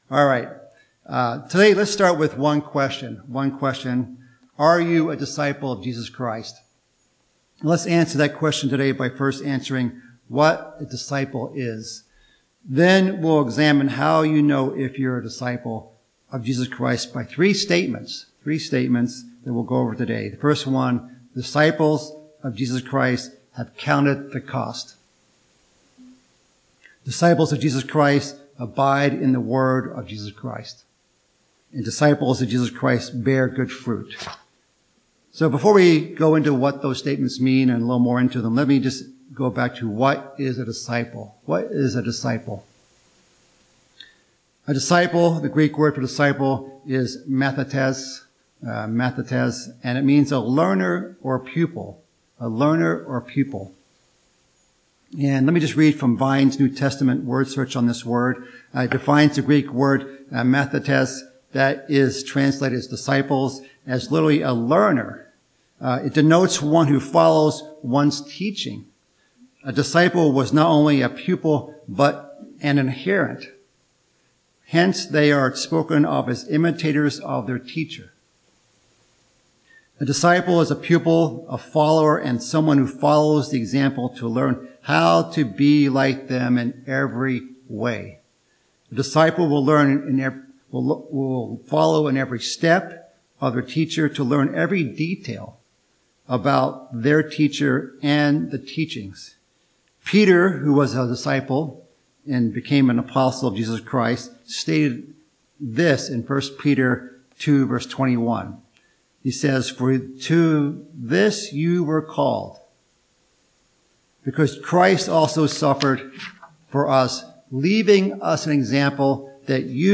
It's a question worth asking ourselves - are you a disciple of Jesus Christ? This sermon covers three Biblical ways to discern the answer.